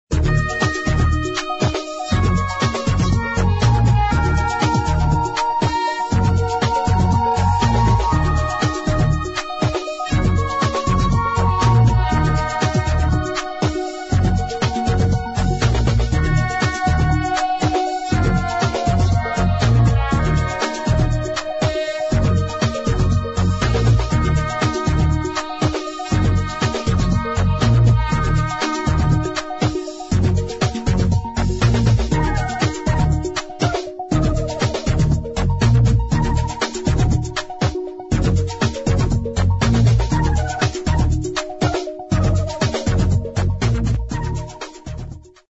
[ HOUSE | DISCO | ROCK ]